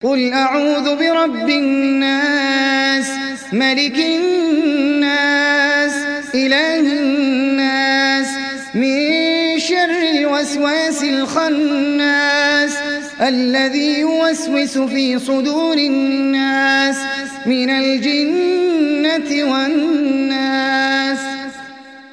সূরা আন-নাস mp3 ডাউনলোড Ahmed Al Ajmi (উপন্যাস Hafs)